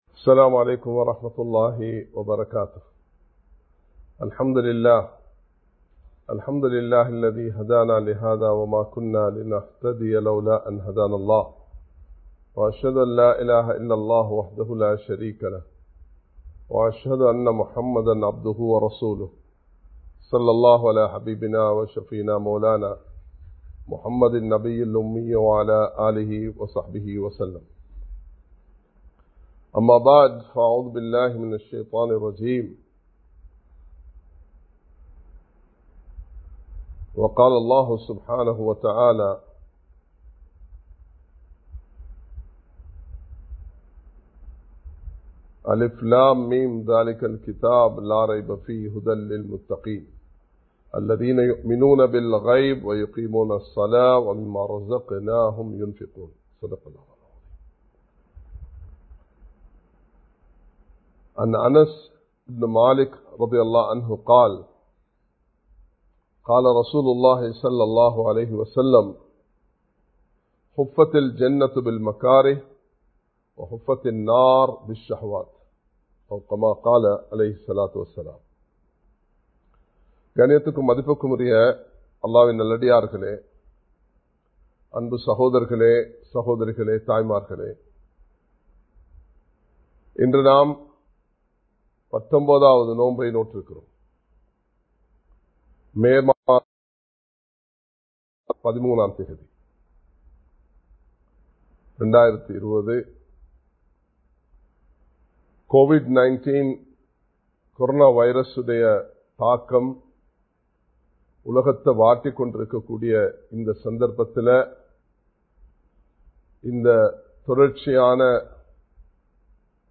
நல் அமல்களும் சிரமங்களும் (Good Deeds and difficulties) | Audio Bayans | All Ceylon Muslim Youth Community | Addalaichenai
Live Stream